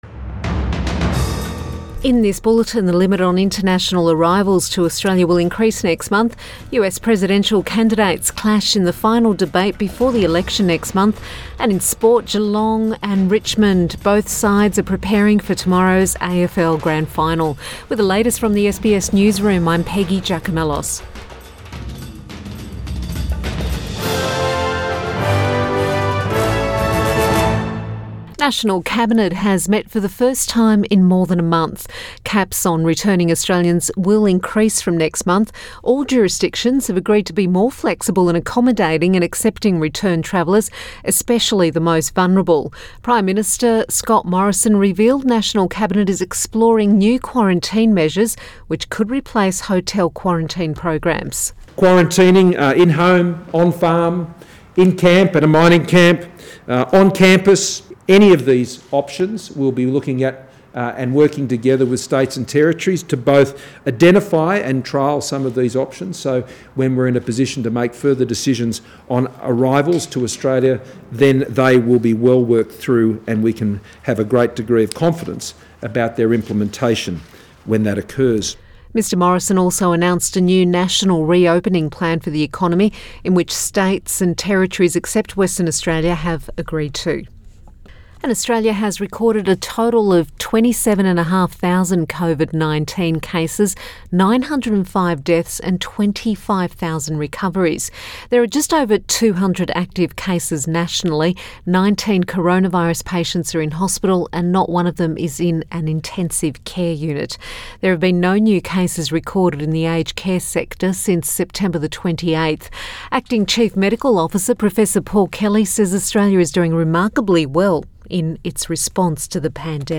PM bulletin 23 October 2020